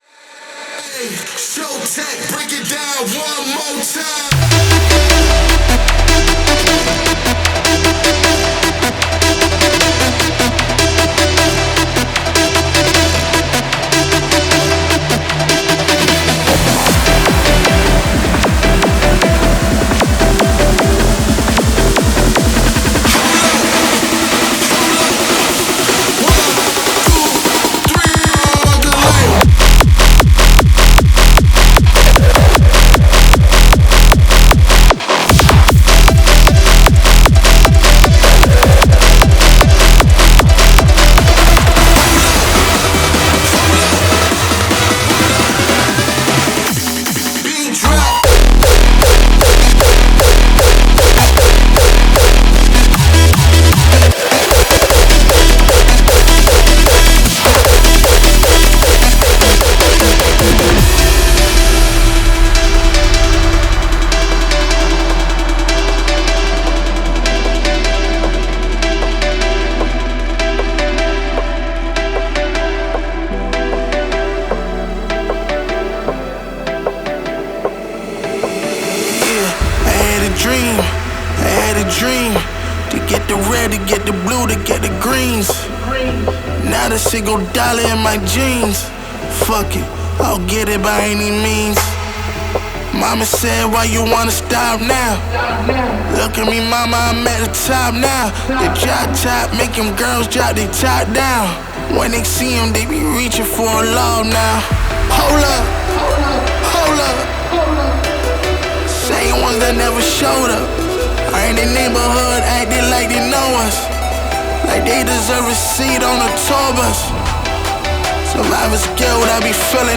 • Жанр: Hardstyle, Dance